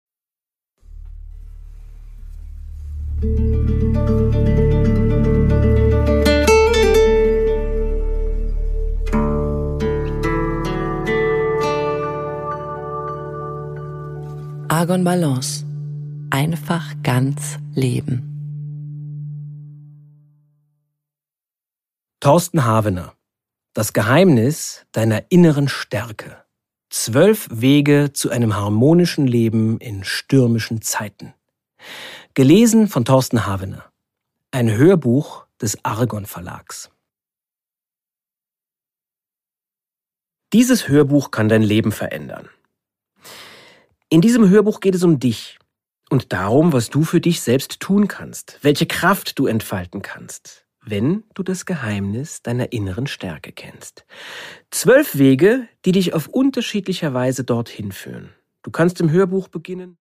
Produkttyp: Hörbuch-Download
Gelesen von: Thorsten Havener